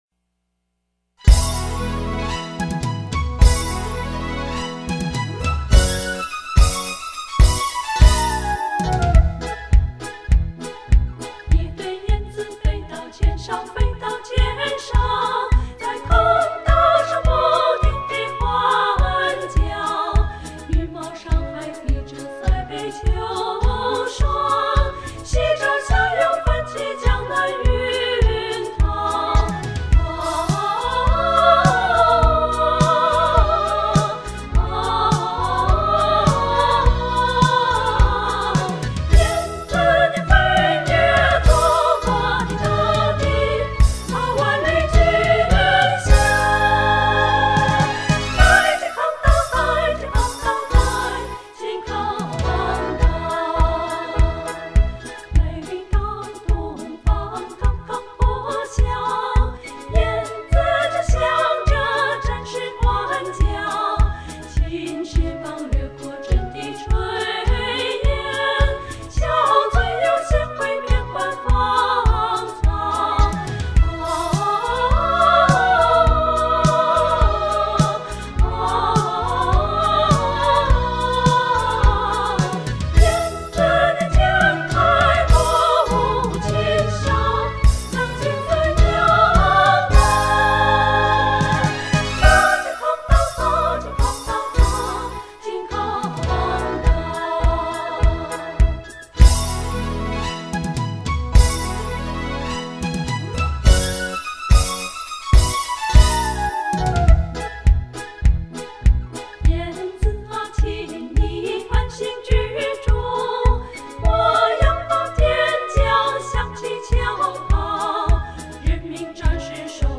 女小合